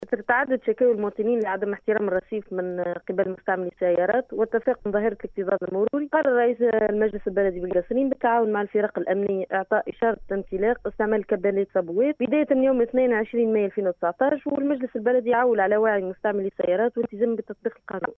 من جهتها صرحت رئيسة لجنة الاشغال ببلدية القصرين المدينة فاطمة دلهومي لراديو السيليوم إف إم ان قرار رئيس البلدية جاء على اثر تعدد تشكيات المواطنين من تفاقم ظاهرة الاكتظاظ المروري وعدم احترام أصحاب السيارات للرصيف المخصص للمترجلين و ركن سياراتهم في الأماكن الغير مخصصة لها.